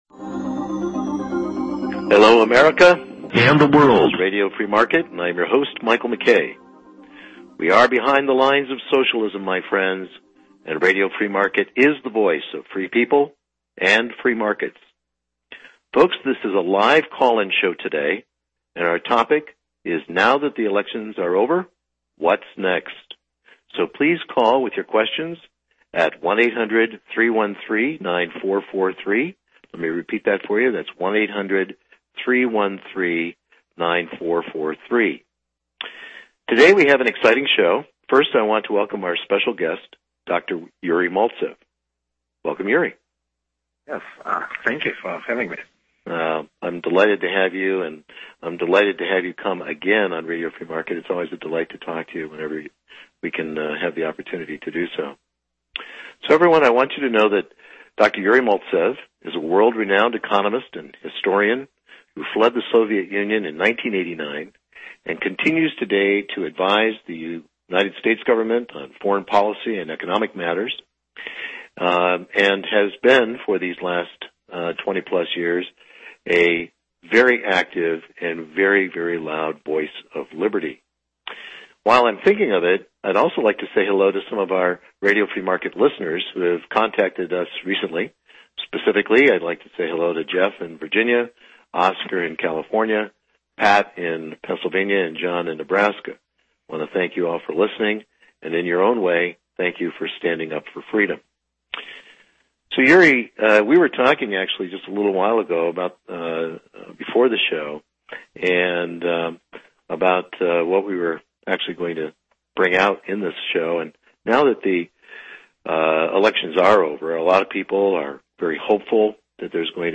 ** SPECIAL LIVE Interview** Now that the Elections are over